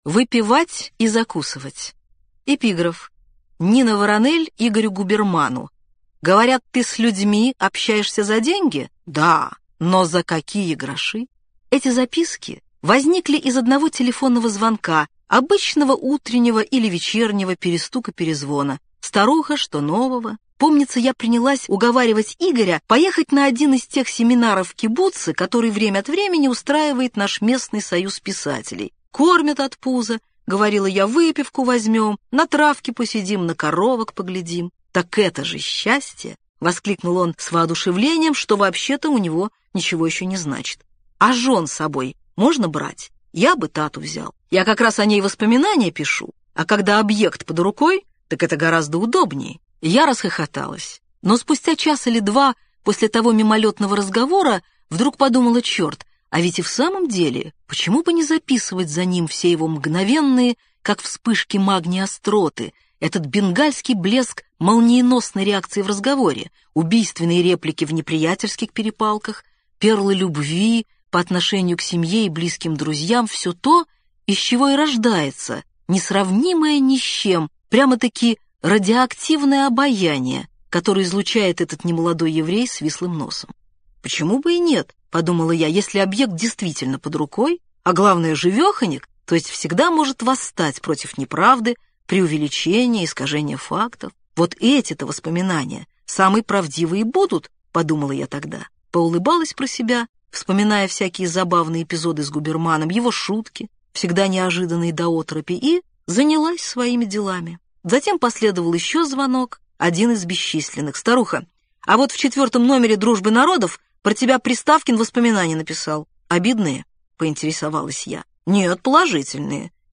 Aудиокнига Выпивать и закусывать Автор Дина Рубина Читает аудиокнигу Дина Рубина.